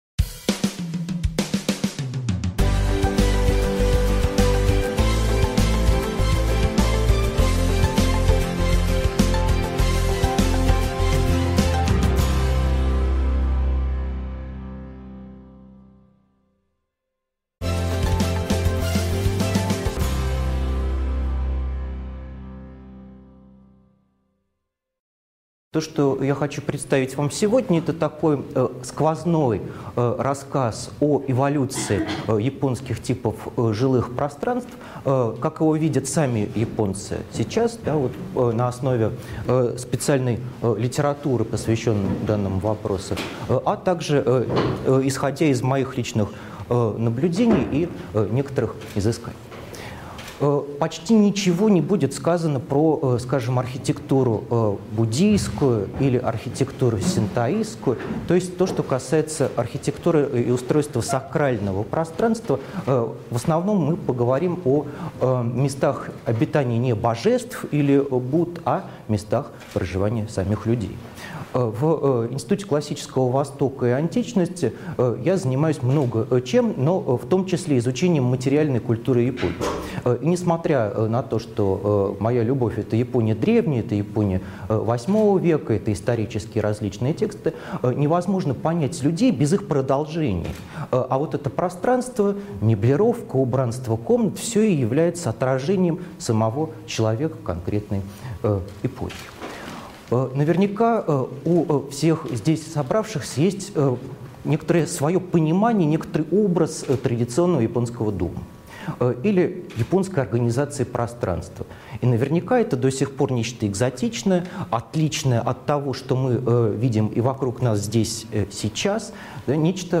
Аудиокнига Традиционный японский дом | Библиотека аудиокниг